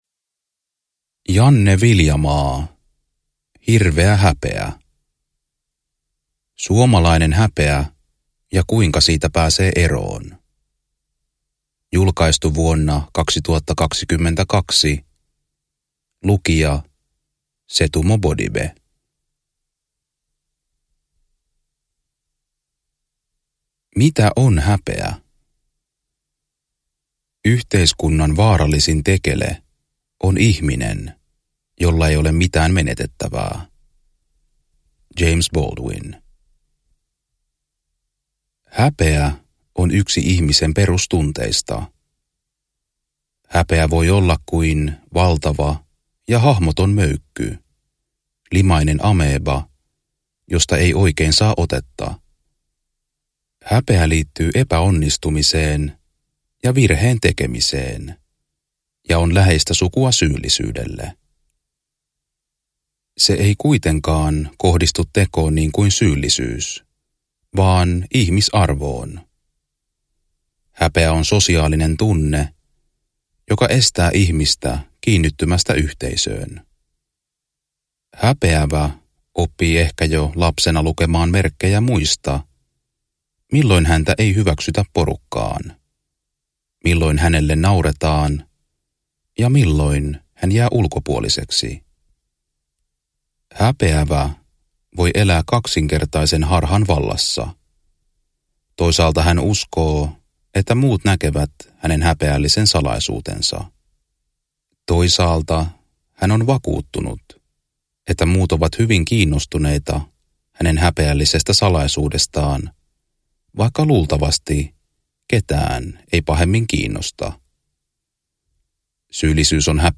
Hirveä häpeä – Ljudbok – Laddas ner